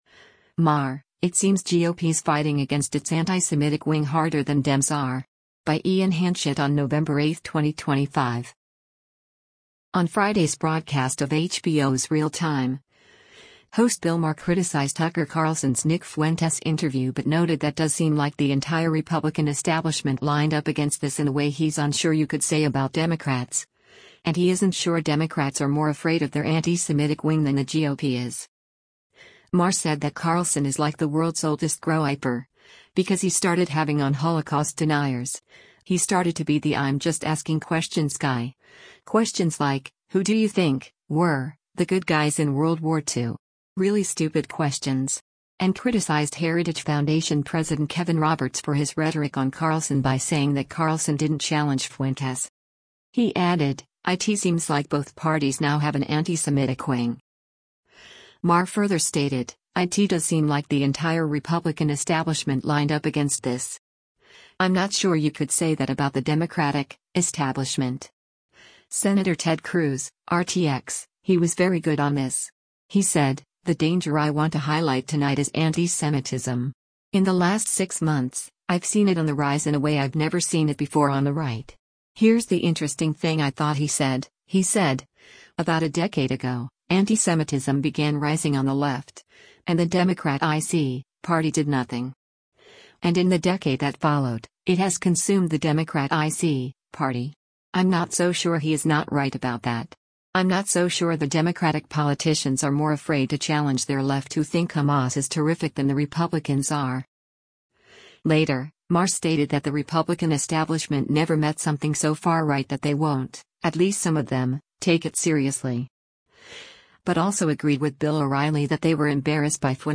On Friday’s broadcast of HBO’s “Real Time,” host Bill Maher criticized Tucker Carlson’s Nick Fuentes interview but noted that “does seem like the entire Republican establishment lined up against this” in a way he’s unsure you could say about Democrats, and he isn’t sure Democrats are more afraid of their antisemitic wing than the GOP is.